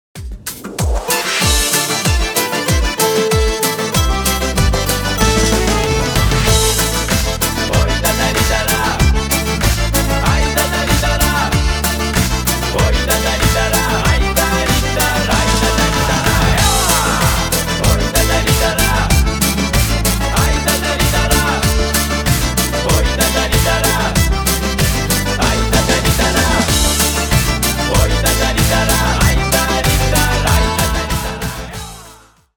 Поп Музыка
кавказские